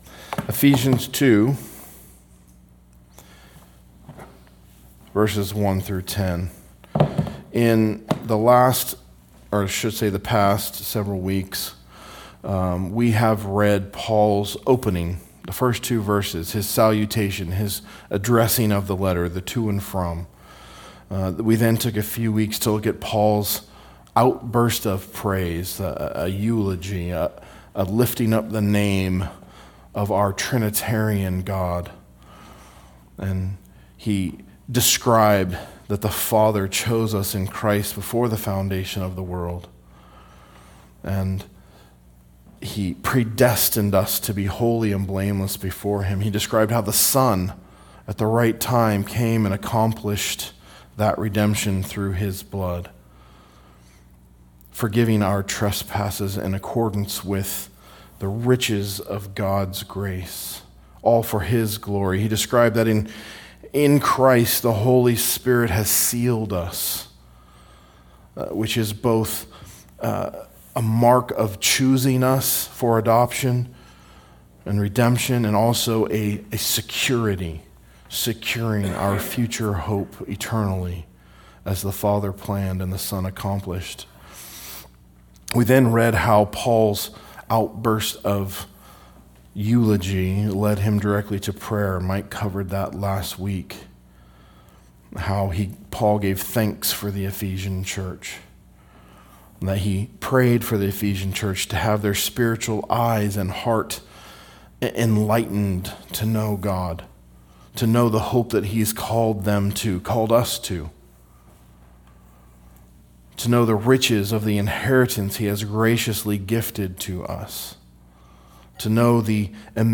A message from the series "Ephesians." Exposition of Ephesians 2:1-10